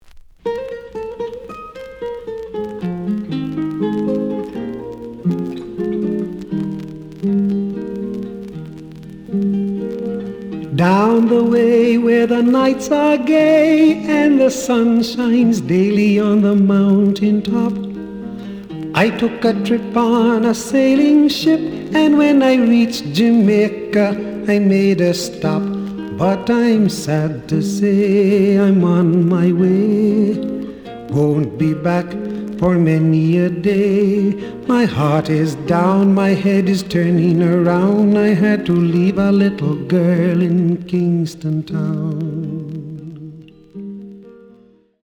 The audio sample is recorded from the actual item.
●Genre: Reggae